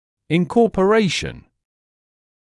[ɪnˌkɔːpə’reɪʃn][инˌкоːпэ’рэйшн]включение, вхождение